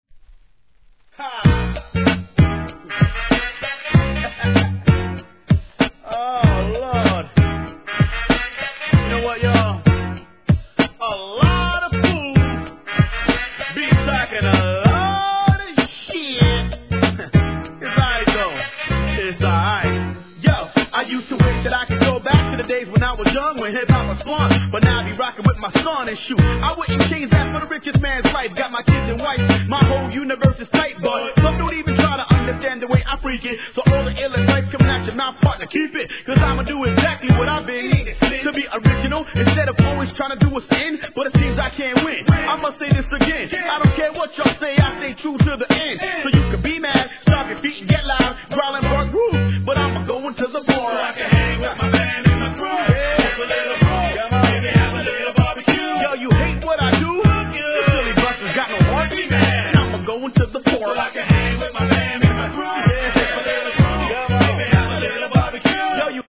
HIP HOP/R&B
ヒューマン･ビート･ボックス集団